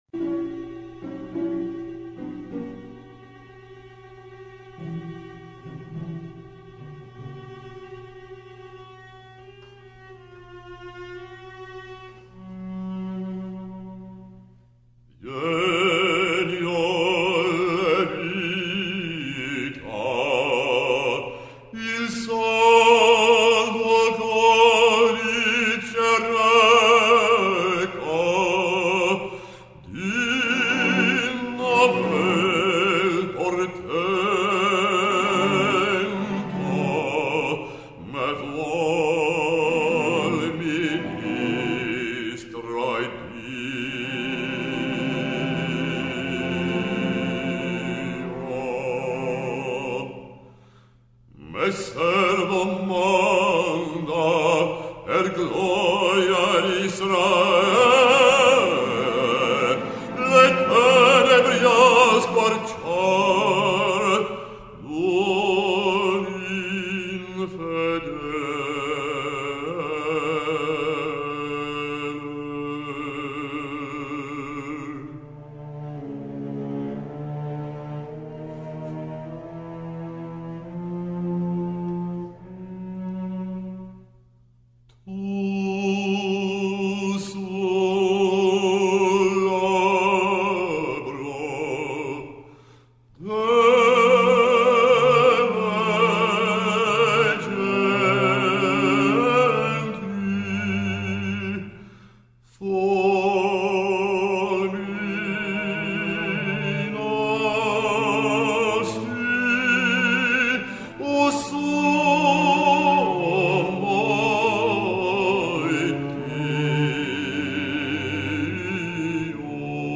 Opera Demos